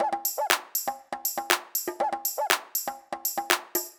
ITA Beat - Perc Mix 1.wav